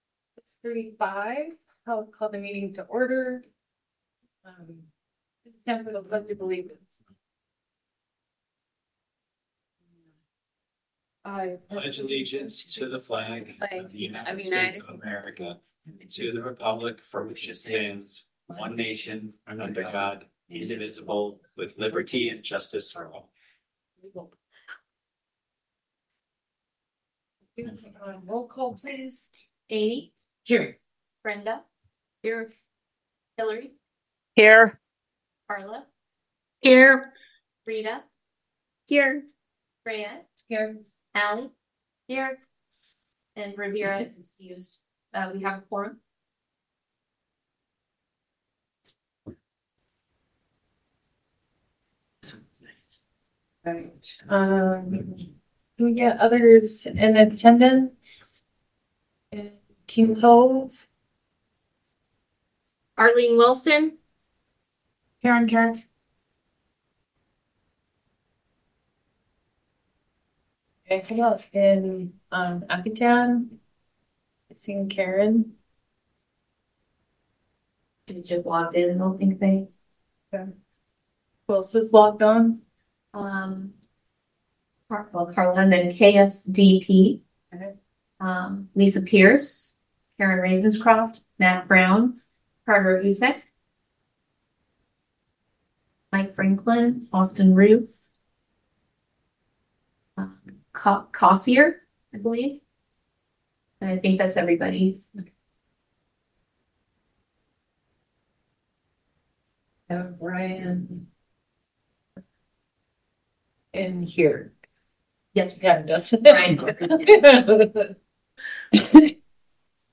MP3+PDF: Regular Meeting of the AEBSD School Board at 6:30PM on Tues, Jan 27, 2026